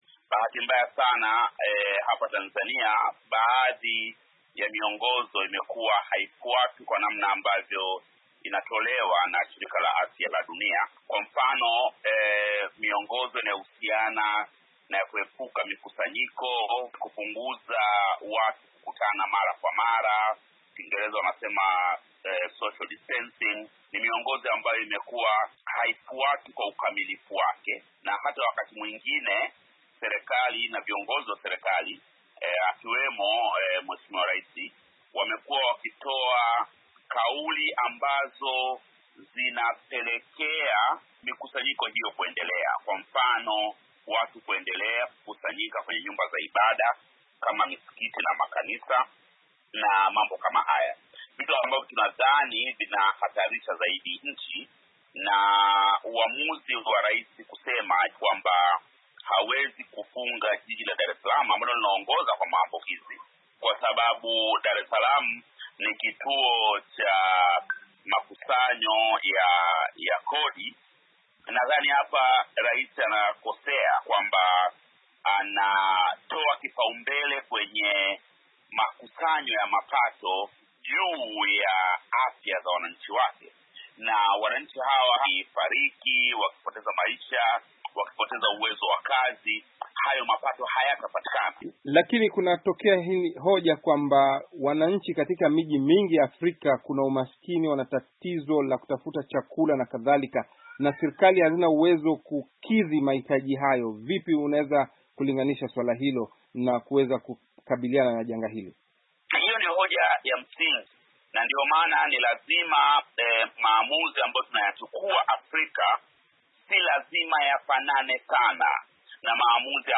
Katika mahojiano siku ya Alhamisi Kabwe alikosoa msimamo wa Rais Magufuli wa kukataa kufunga shughuli zote za biashara na utawala katika miji ambayo kuna uambukizaji mkubwa zaidi kama vile Dar es Salaam, kama wanavyofanya nchi nyingine duniani.